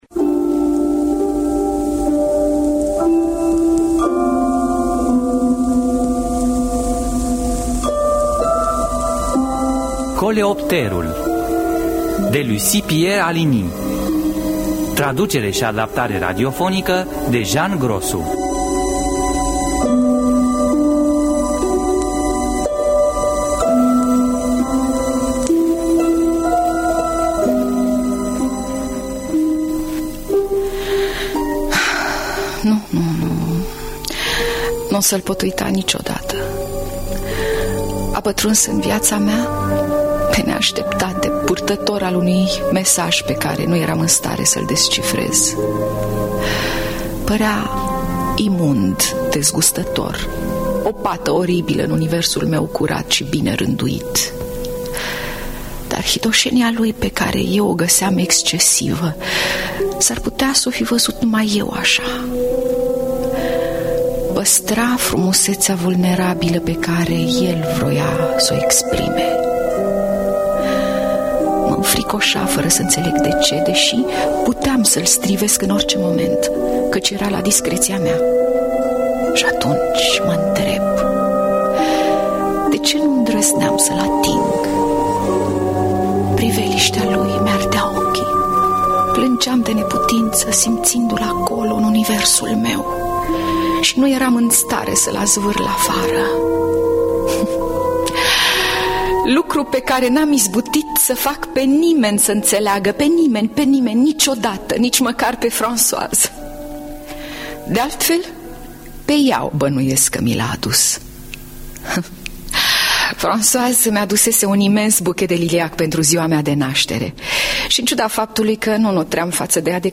Adaptarea radiofonică de Jean Grosu.